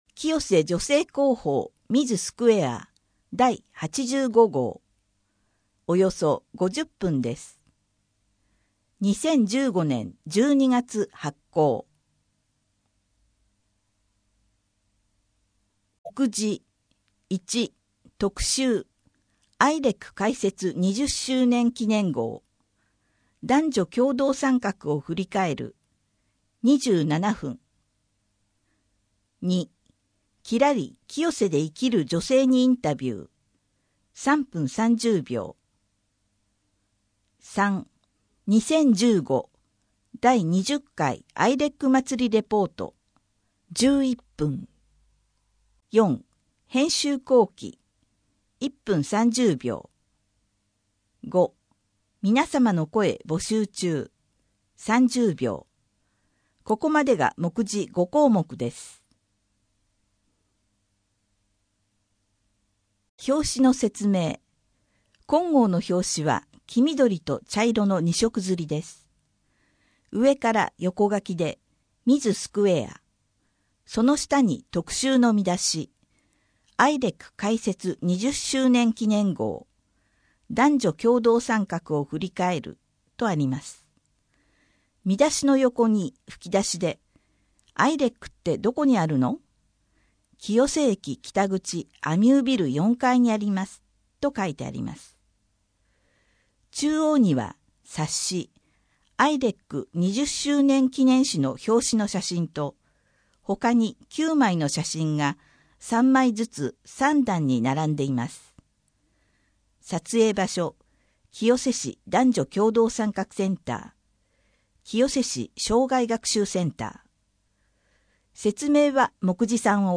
アイレックだより 6面 きらり清瀬で生きる女性にインタビュー 7面 アイレックまつりレポート 8面 アイレックまつりレポート 編集後記など 声の広報 声の広報は清瀬市公共刊行物音訳機関が制作しています。